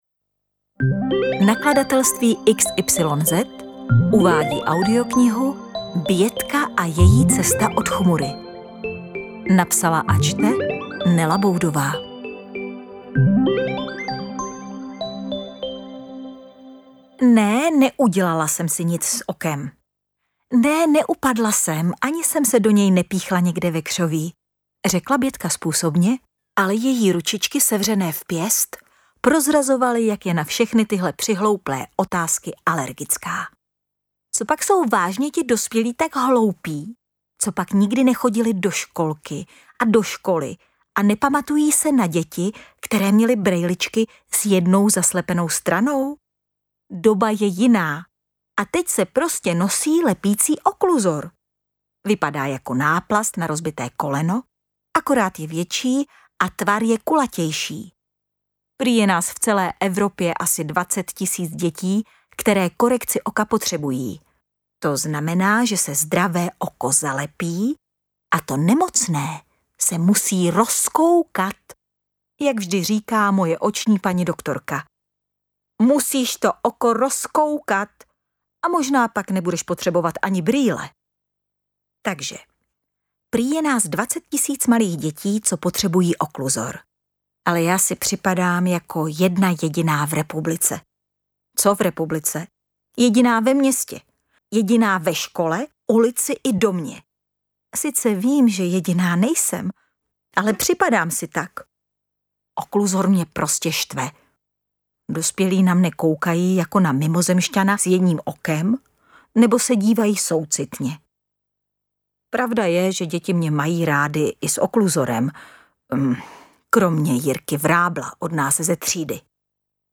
Interpret:  Nela Boudová
AudioKniha ke stažení, 10 x mp3, délka 2 hod. 18 min., velikost 316,2 MB, česky